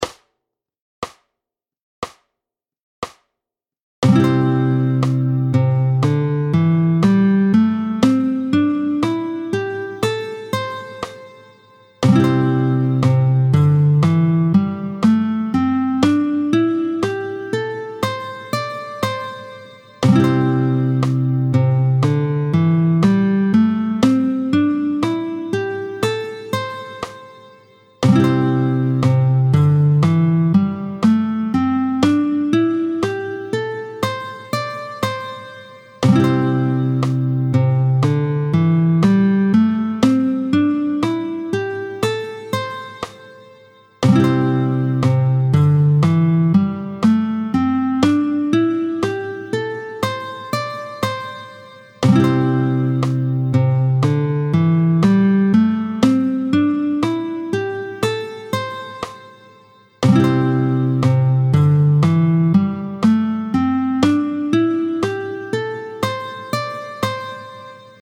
32-04 Do majeur doités 1 et 2, tempo 60